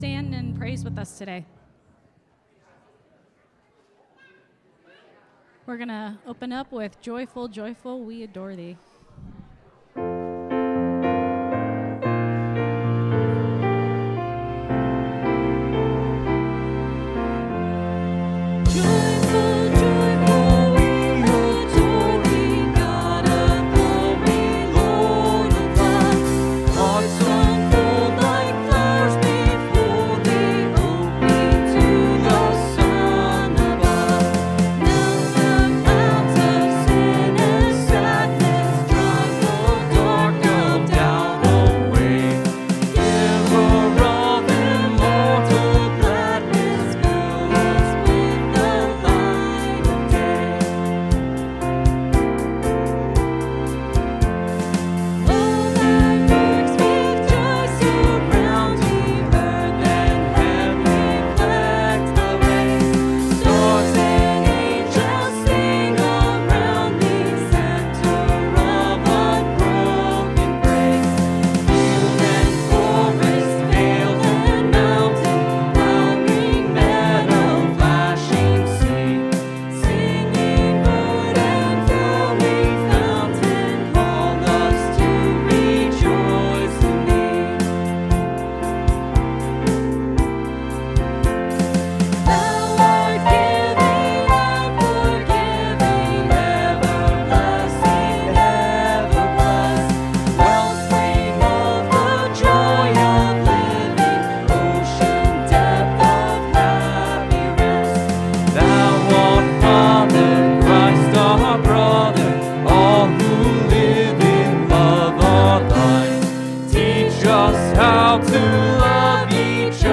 (Sermon starts at 22:35 in the recording).